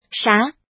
怎么读
shá